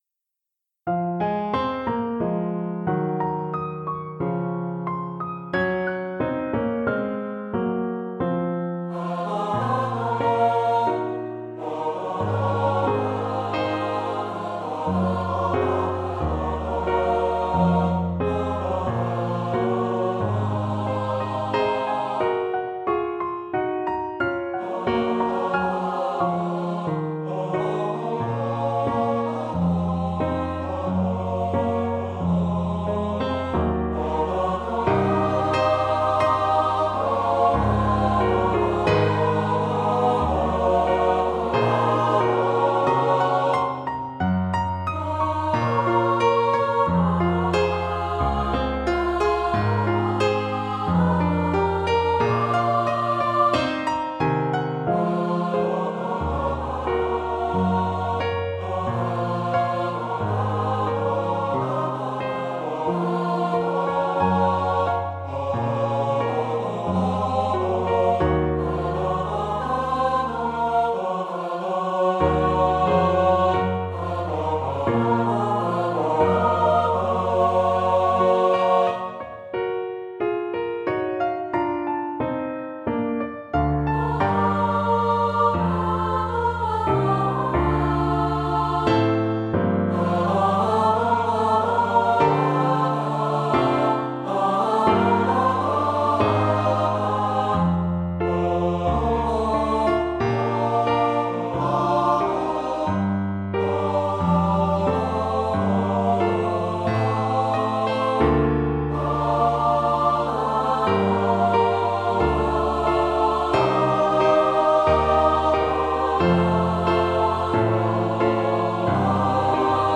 for SATB Chorus, Tenor and Soprano solos, and Piano